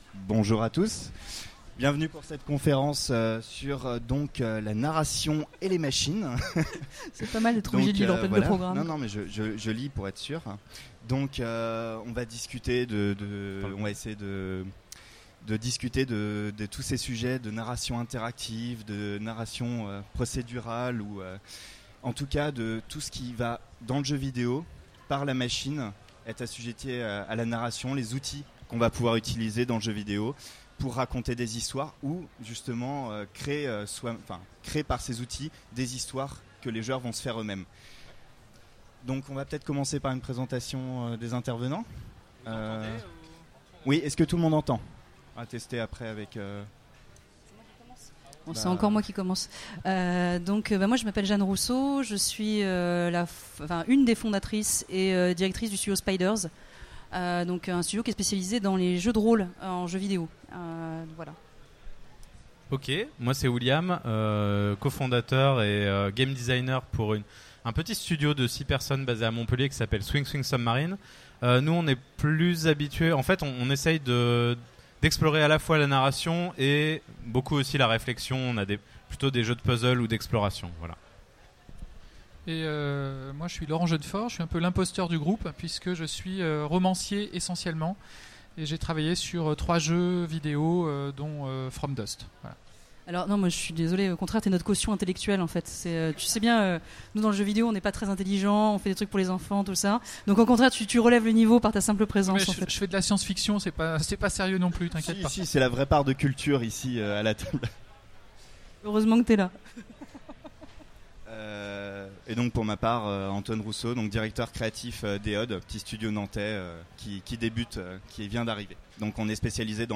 Utopiales 2016 : Conférence Jeux vidéo : machines et nouvelles formes de narration ?